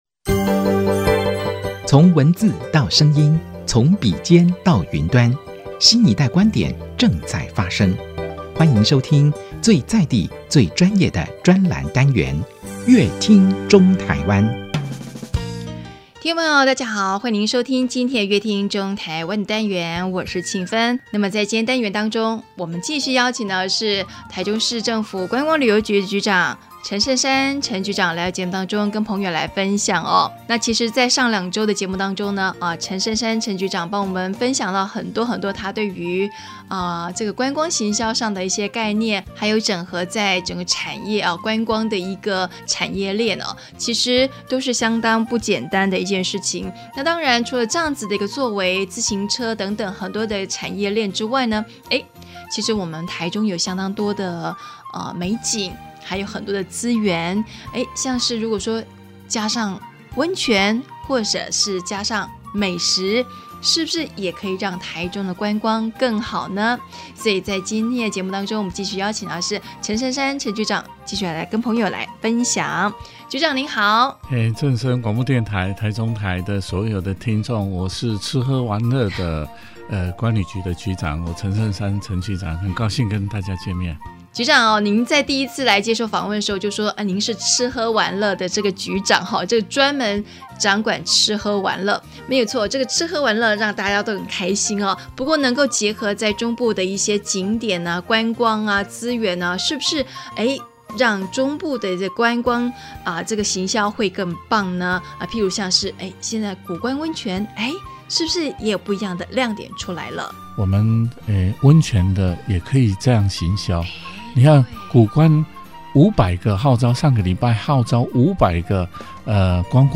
本集來賓：臺中市政府觀光旅遊局陳盛山局長 本集主題：結合物產優勢 行銷台中 本集內容： 泡湯文化能不能成為台中